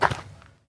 pedology_clay_footstep.1.ogg